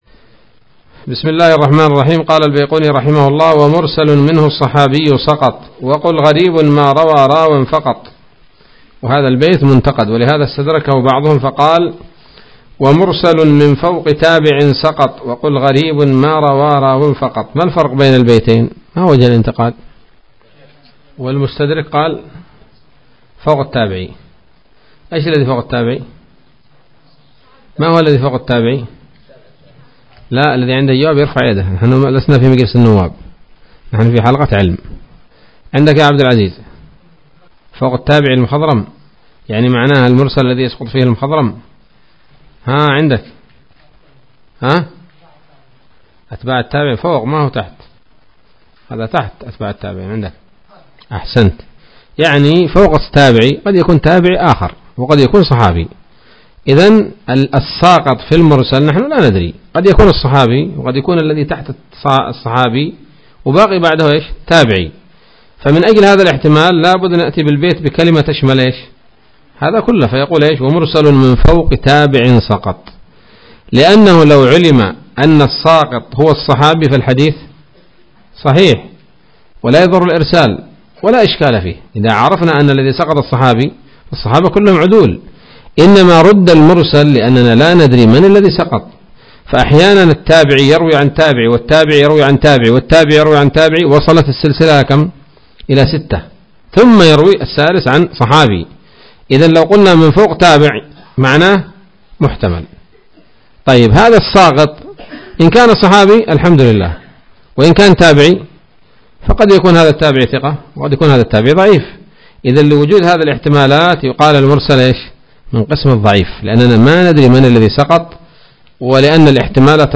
الدرس الثاني والعشرون من الفتوحات القيومية في شرح البيقونية [1444هـ]